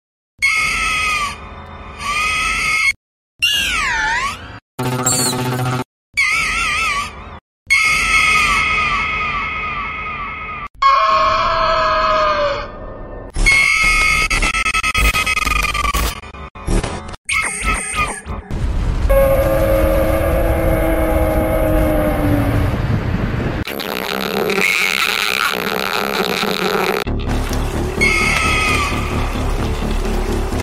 Bird Sound Effects Free Download